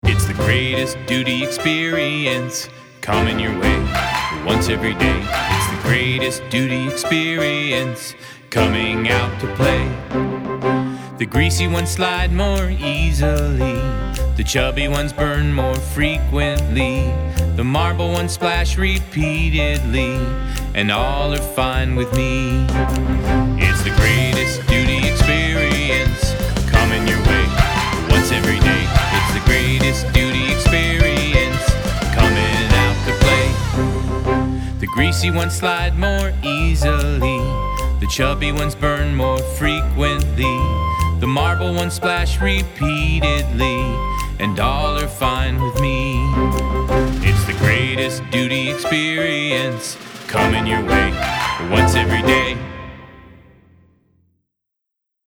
This song is very catchy.  And very fun.